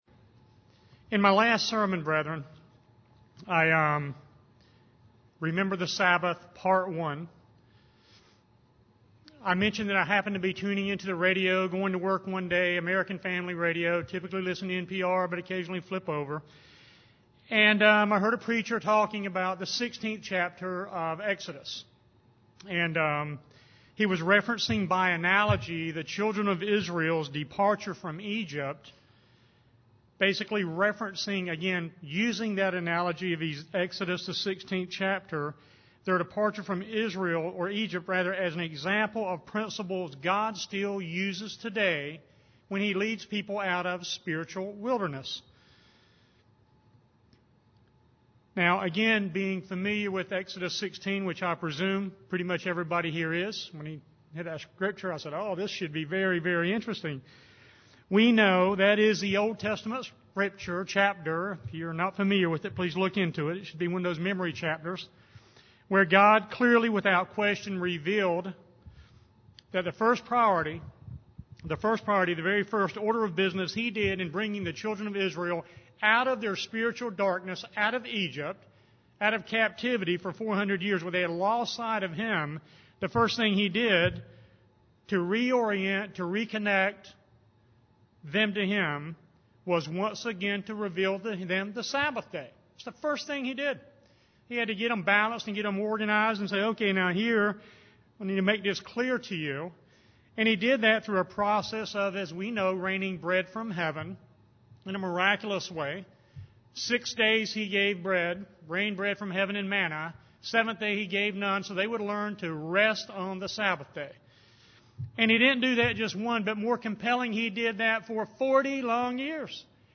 Given in Raleigh, NC
UCG Sermon Studying the bible?